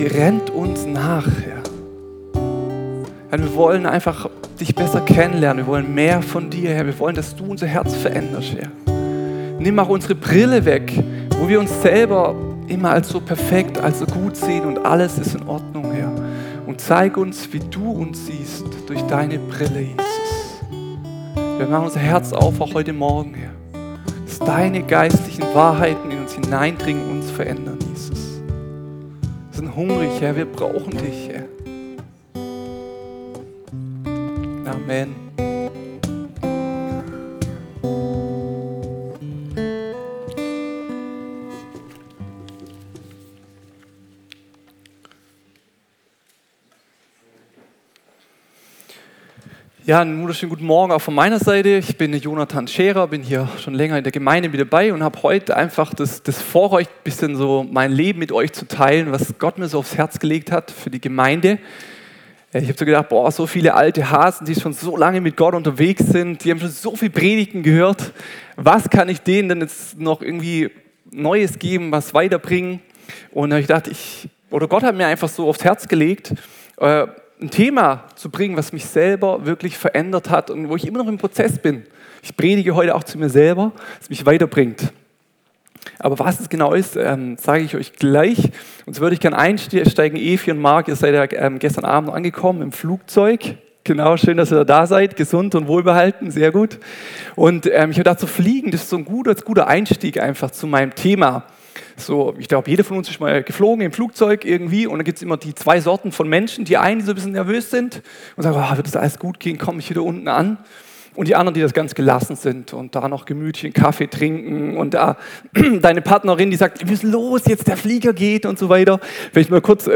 Sonntagspredigten
Wöchentliche Predigten des Christlichen Gemeindezentrums Albershausen